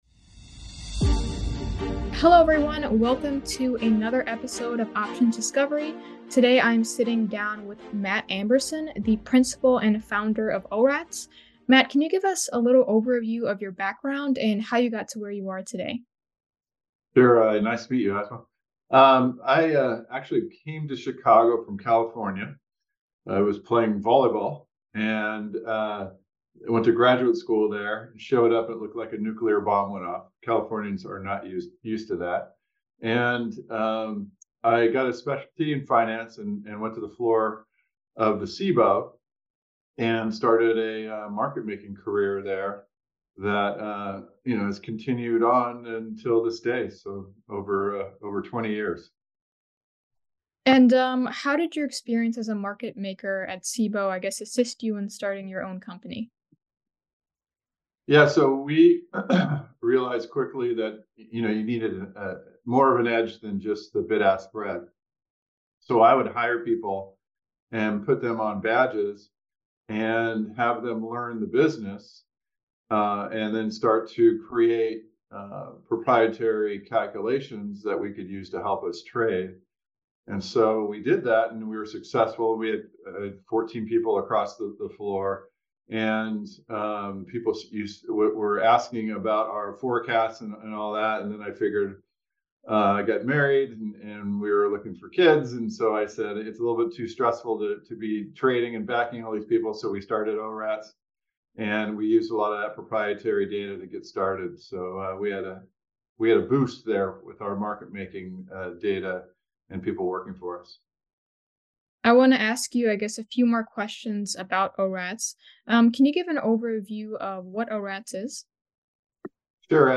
Discusses Trading Strategies for Different Market Movements in this Options Discovery Full Interview